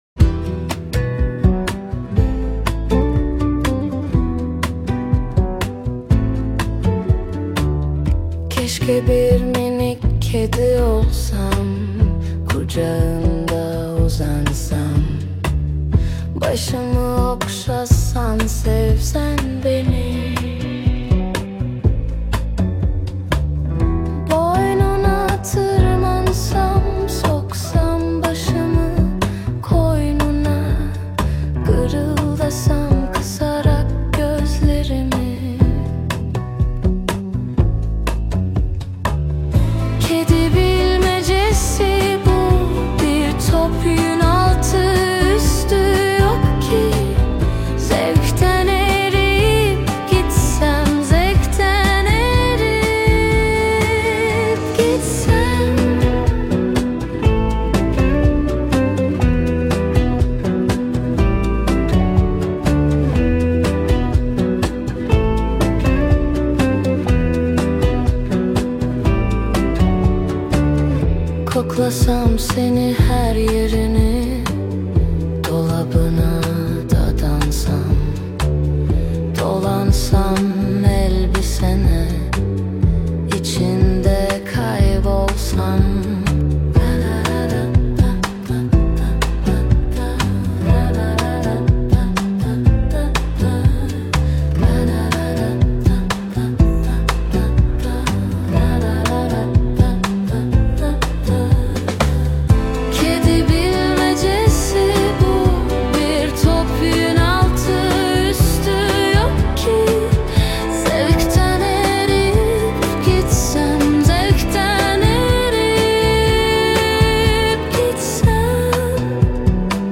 Tür : Pop